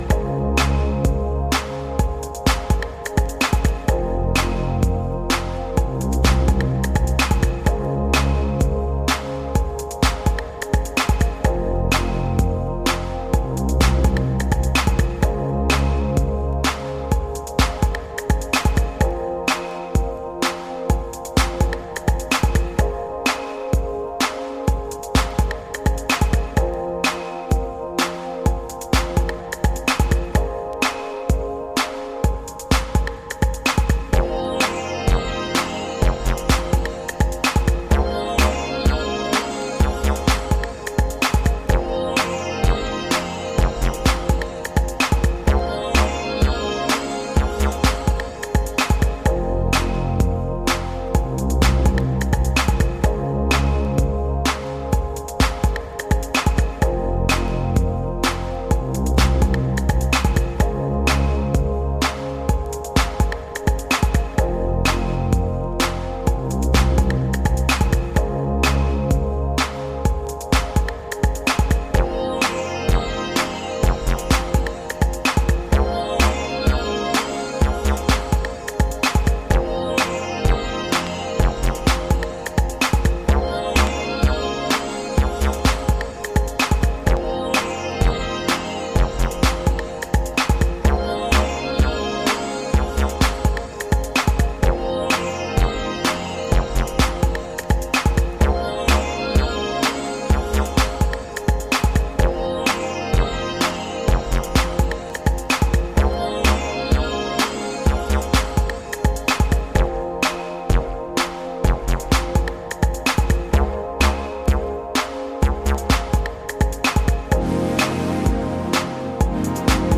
Disco Funk Boogie